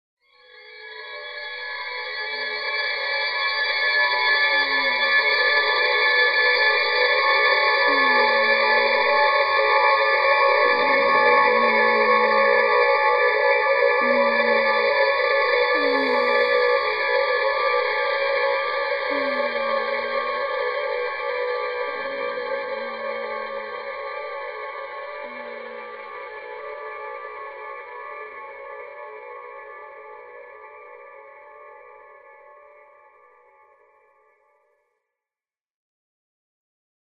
独自の音響効果やリズムの組み合わせによって、脳波の響きを表現しています。
この曲は、聴く人を深い集中やリラックス状態に導き、脳の活性化や創造性の向上を促すことを目指しています。